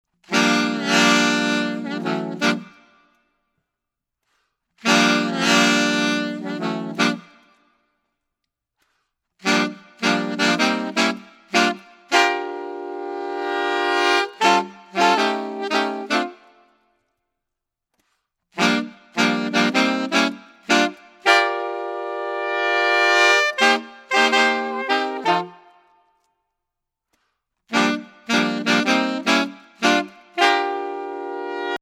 Gattung: Saxophonquartett (AAA, AAT, AATA, AATT)
Instrumentalnoten für Saxophon Tonprobe
ist eine spielerische, swingende Komposition.